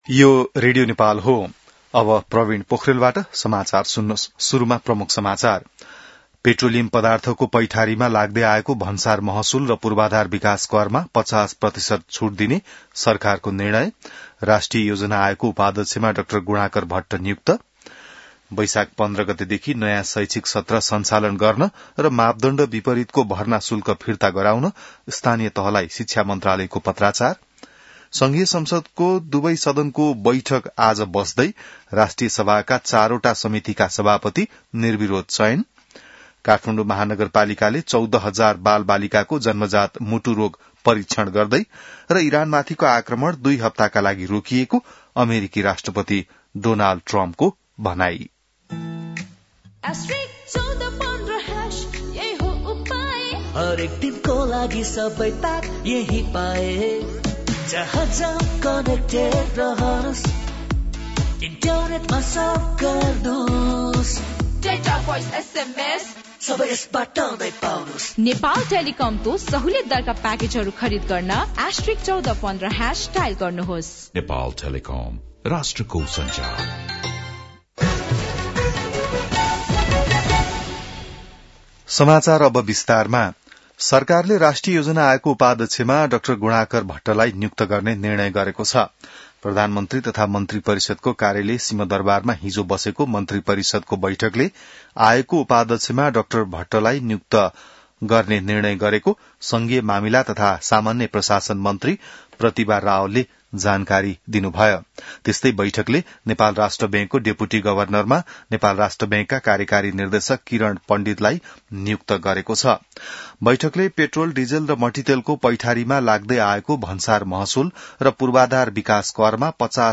बिहान ७ बजेको नेपाली समाचार : २५ चैत , २०८२